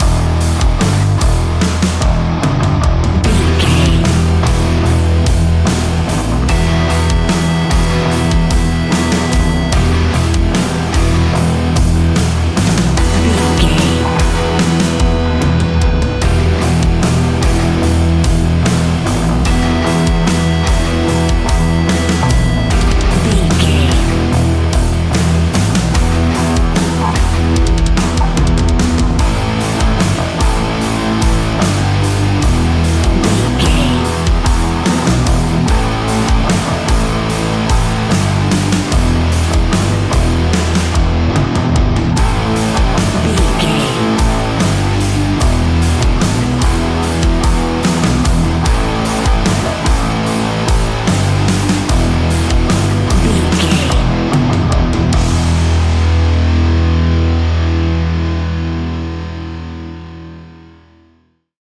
Ionian/Major
E♭
guitars
instrumentals